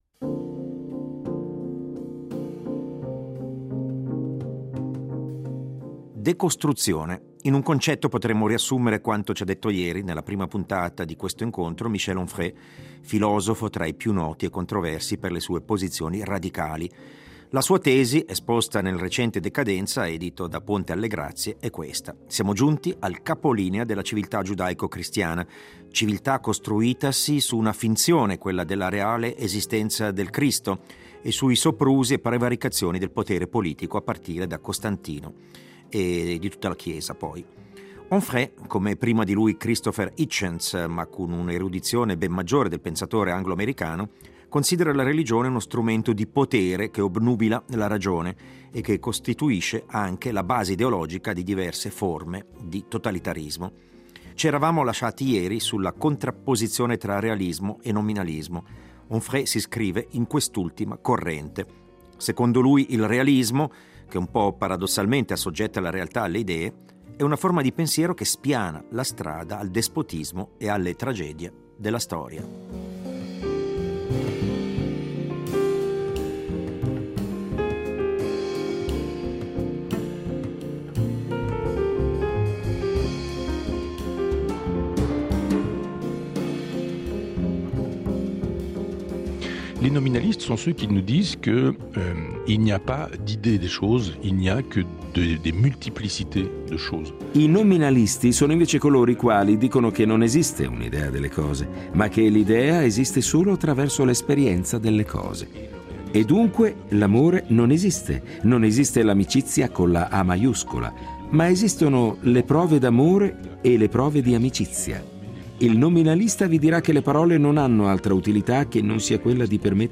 In una lunga conversazione